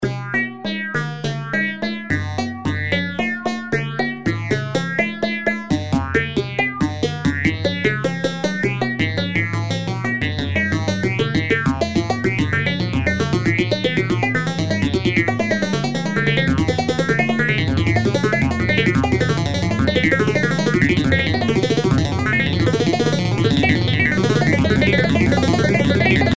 No segments are repeated.